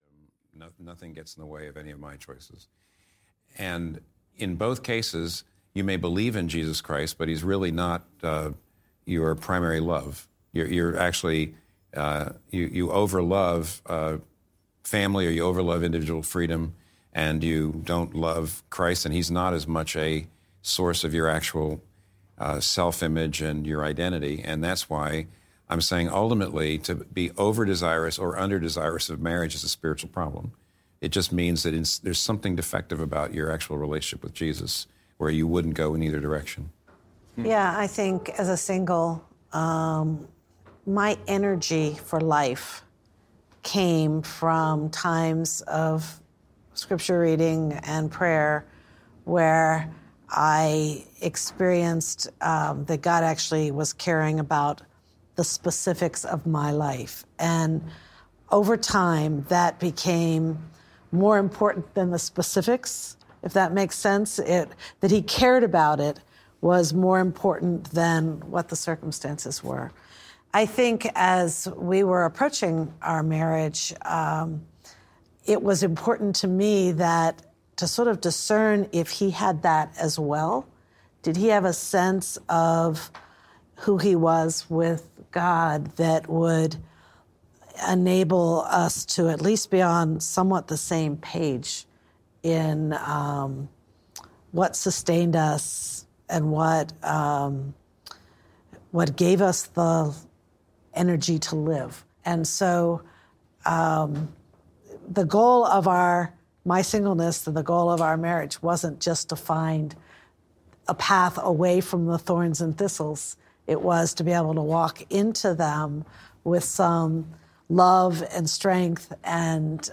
Unlike a traditional audiobook’s direct narration of a book’s text, The Meaning of Marriage includes high-quality, live audio sessions from the author that cover this important Bible-based topic. These sessions will reflect the ambiance of the unique recording locations, immersing the listener into the teaching.